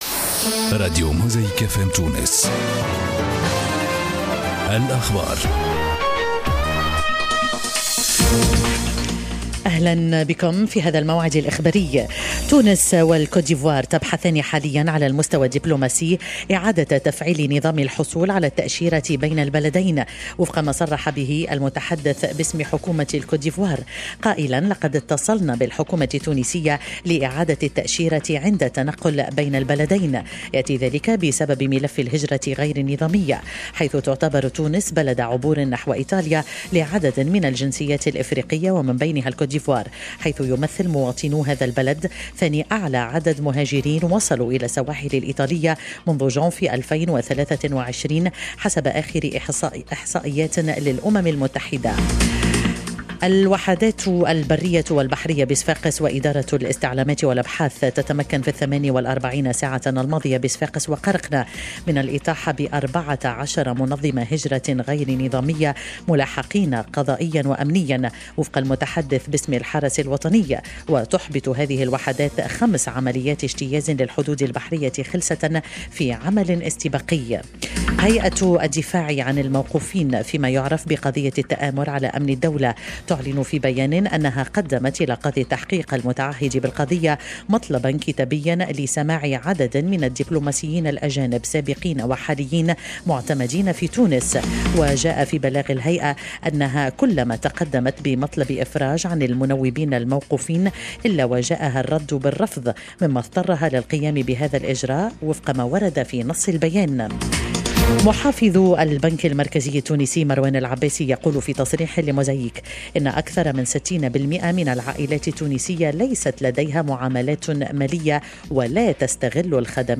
استمعوا إلى تسجيلات موجز الأخبار لكامل اليوم - موقع موزاييك (1)
كل التسجيلات لنشراتنا الإخبارية بفقرة فلاش انفو تابعوا الأخبار عبر موجات موزاييك ف م، الاذاعة رقم واحد في تونس البوابة الإخبارية التي تتيح لكم متابعة مستمرة لجديد الأنباء